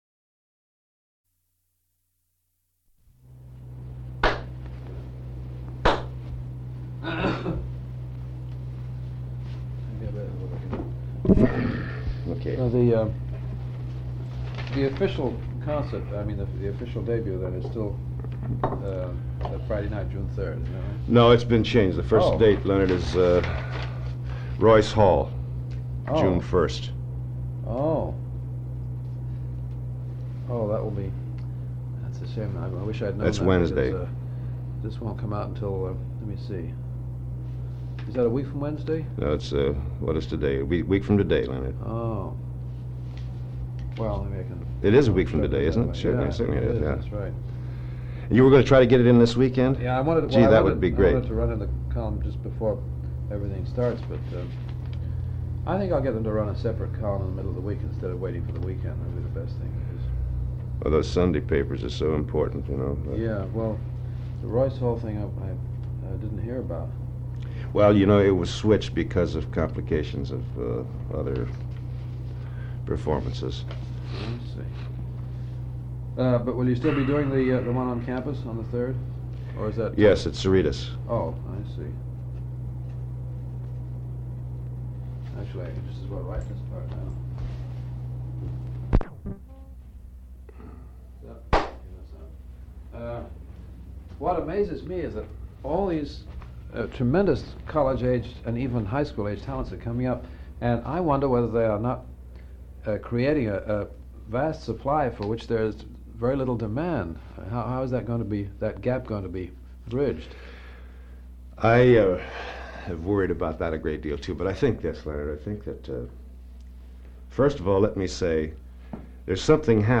Stan Kenton Interview & Blindfold Test
Item from Leonard Feather Collection: Recording identified as most likely Leonard Feather interviewing Stan Kenton and Kenton participating in one of Feather's blindfold tests.